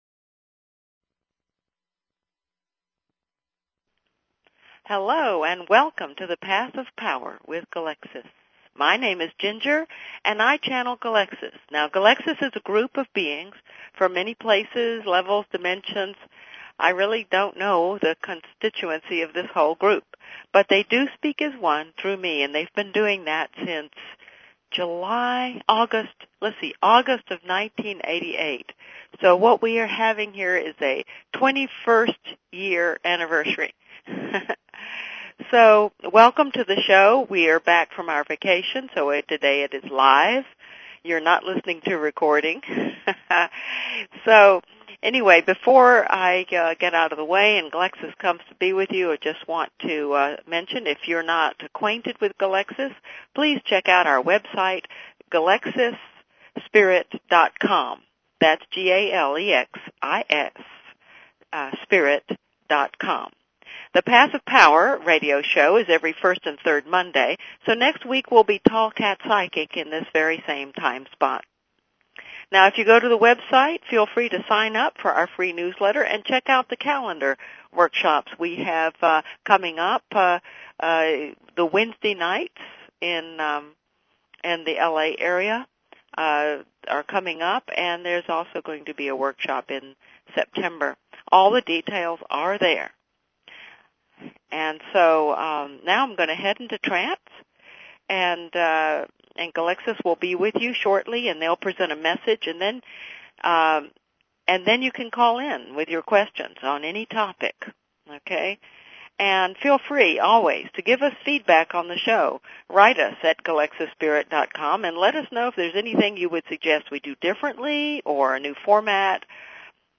Talk Show Episode, Audio Podcast, Path_of_Power and Courtesy of BBS Radio on , show guests , about , categorized as